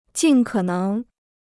尽可能 (jǐn kě néng): as far as possible; to do one's utmost.